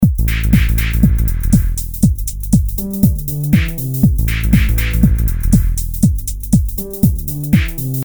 124 Bpm 平滑的House Groove
描述：使用Ableton Live 8创建的Deep House鼓循环。
标签： 循环 鼓楼
声道立体声